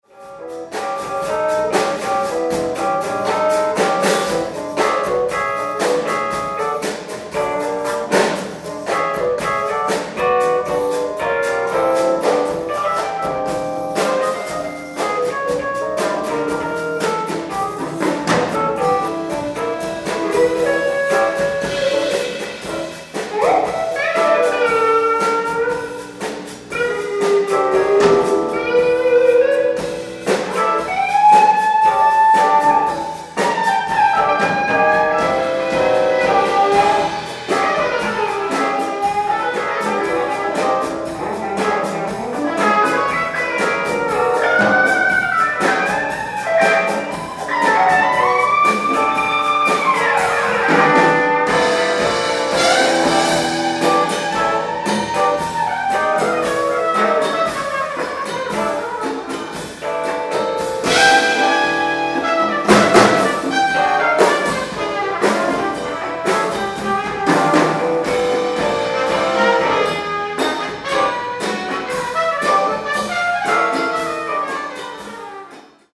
ライブ・アット・フェニックス、アリゾナ 02/15/2022
※試聴用に実際より音質を落としています。